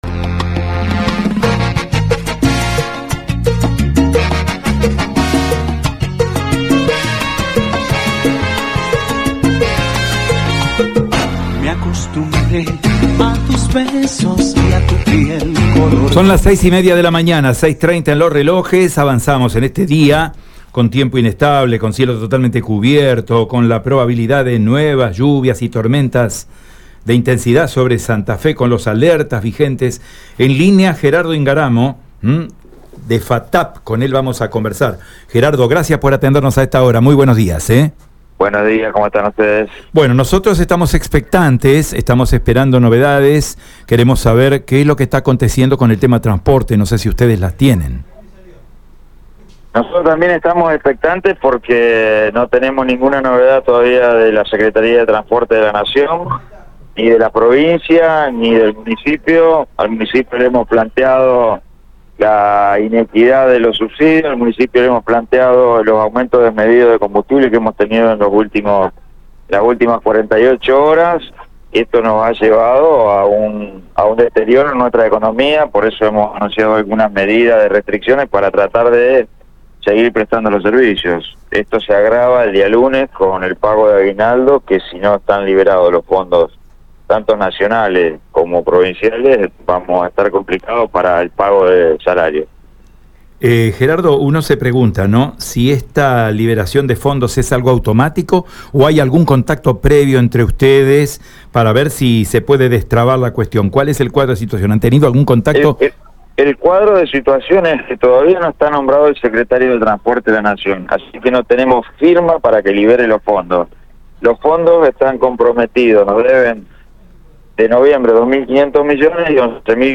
En diálogo con Radio EME